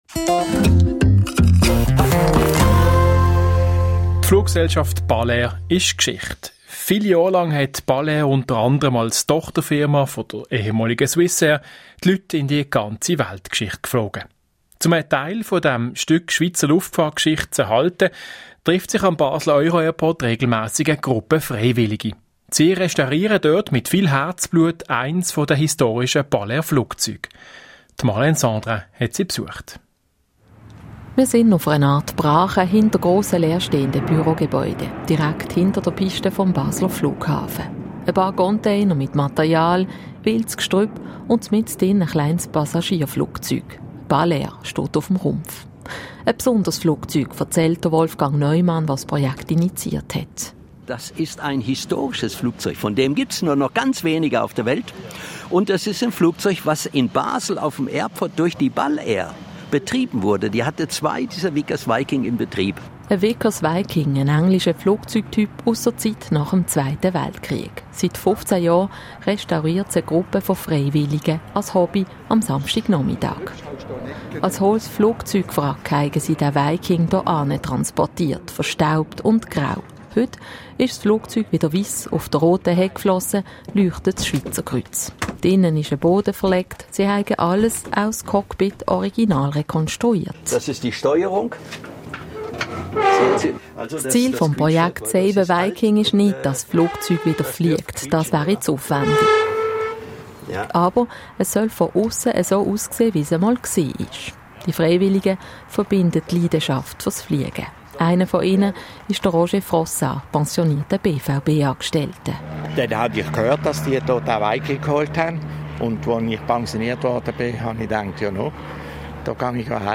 Das Interview wurde am 4. August ausgestrahlt und kann nachfolgend angehört werden – einfach auf den Abspielen-Pfeil ganz links klicken: